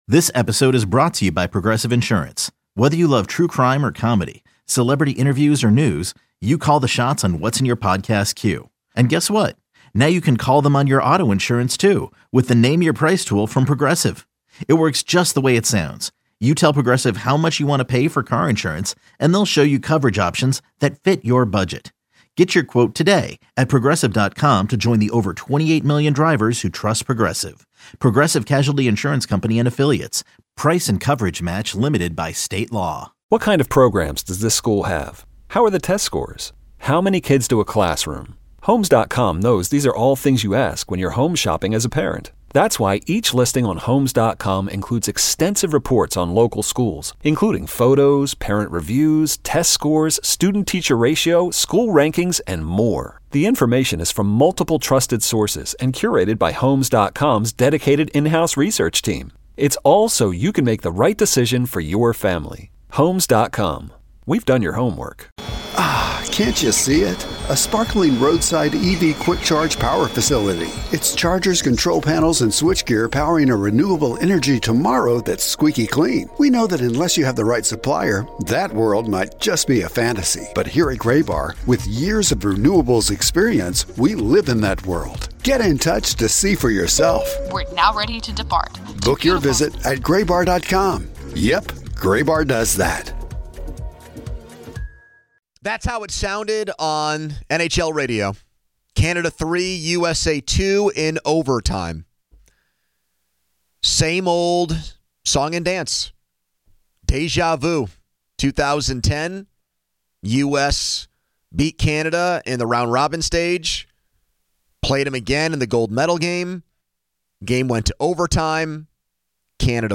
A caller said he loved the game so much last night that he passed up sex to watch it.